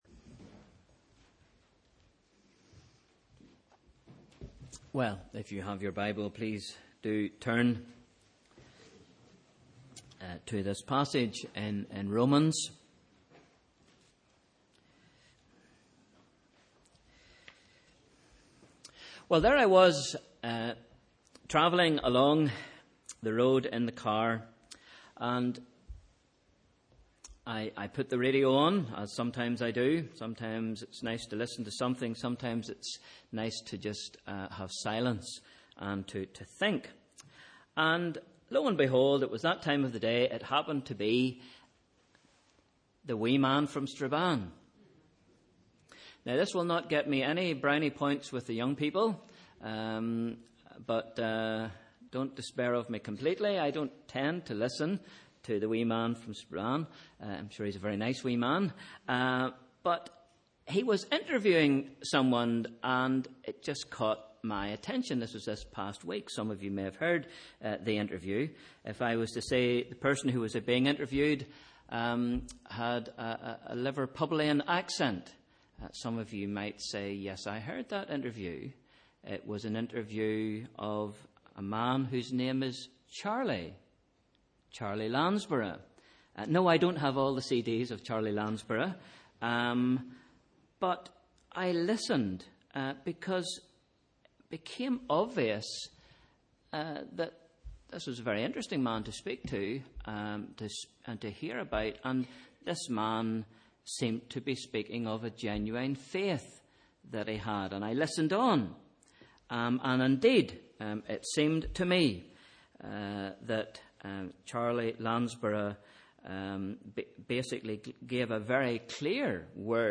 Sunday 28th February – Evening Service